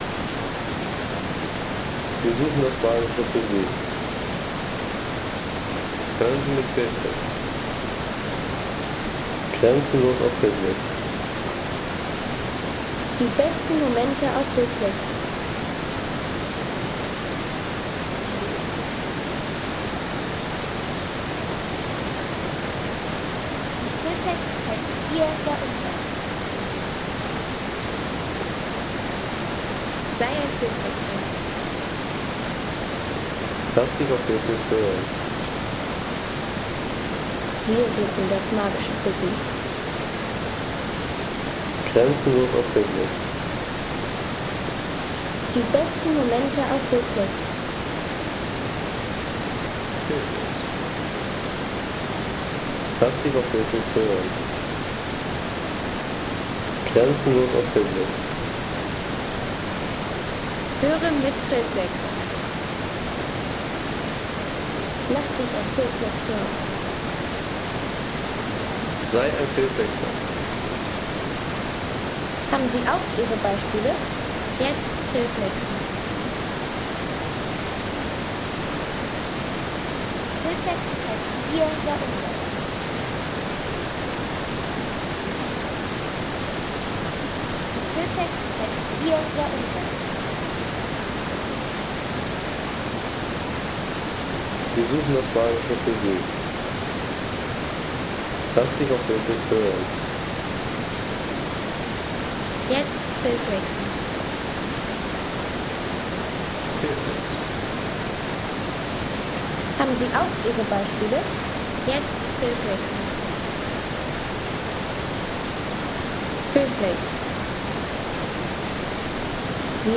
Plitvicer Seen Wasserfall Sound | Feelplex
Ein Wasserfall-Sound mit sehr vielen Hauptdarstellern
Natürliche Wasserfallkulisse aus den Plitvicer Seen mit vielen kleinen Kaskaden, türkisblauem Wasser und Steg-Atmosphäre.
Unzählige kleine Wasserfälle, türkisblaues Wasser und Holzbohlenwege in den Plitvicer Seen.